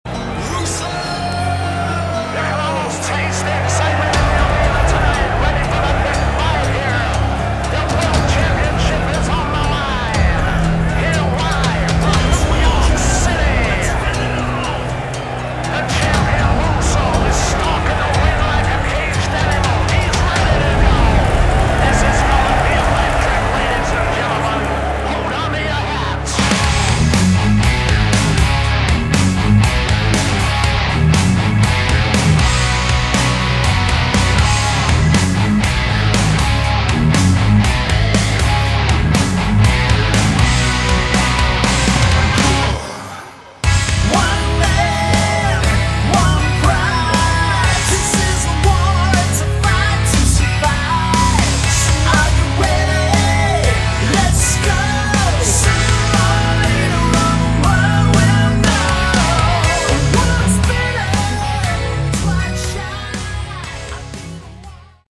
Category: Hard Rock
Vocals, Bass
Guitar
Drums